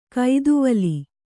♪ kaiduvali